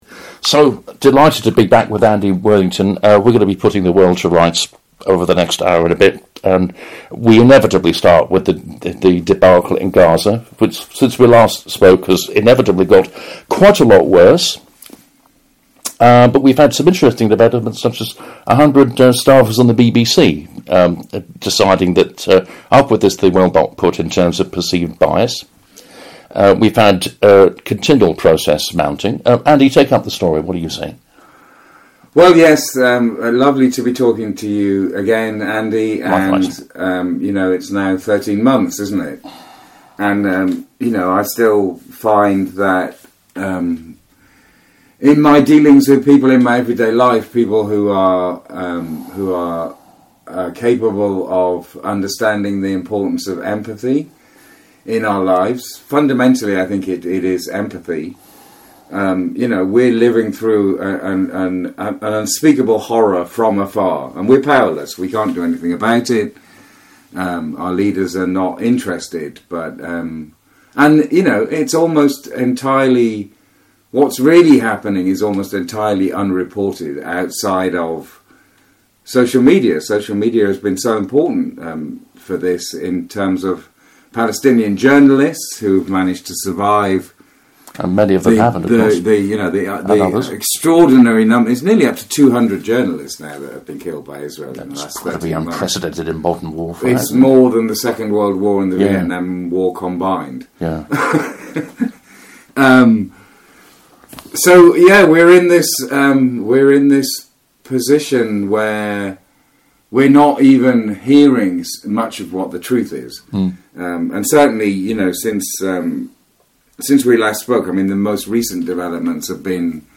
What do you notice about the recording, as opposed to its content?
This is the first in a series of monthly conversations on this platform recorded in his office in South-East London